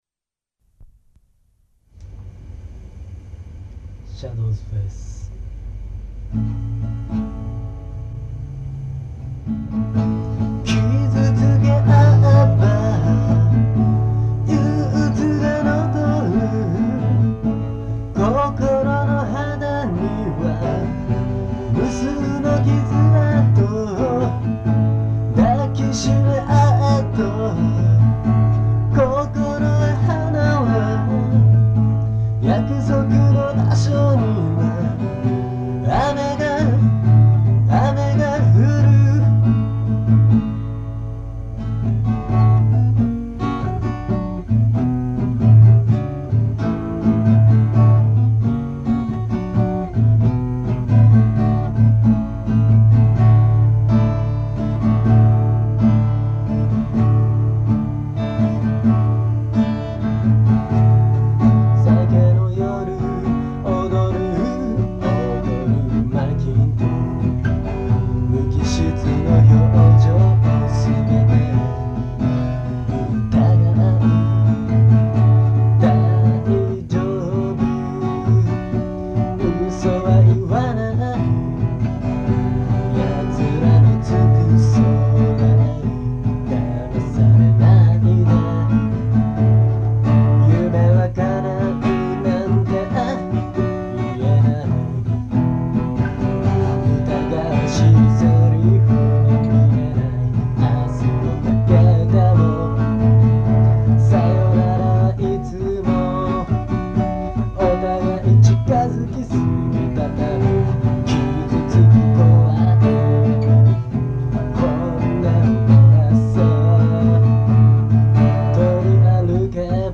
曲の説明 この曲はフォークとロックを融合したものです。